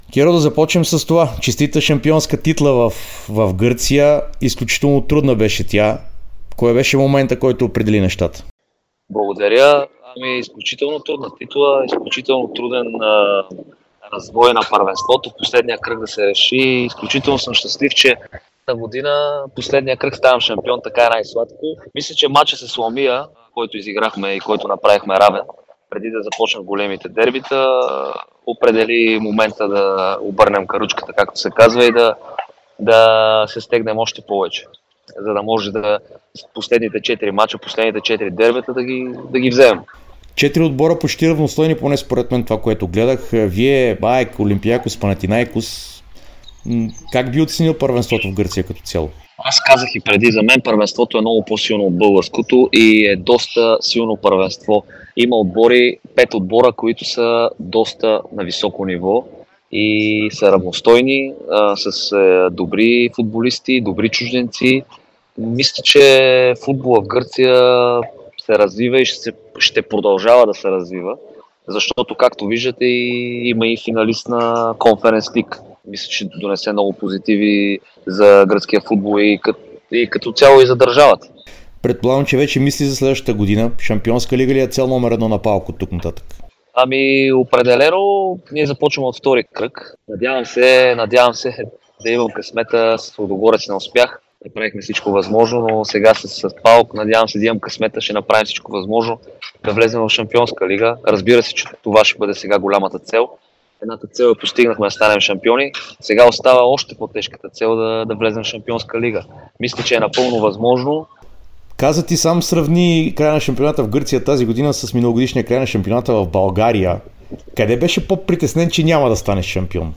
Кирил Десподов стана шампион на ПАОК Солун, а в специално интервю за Dsport и Дарик радио каза, че в последния кръг да триумфираш с титлата е най-слад... (23.05.2024 17:43:52)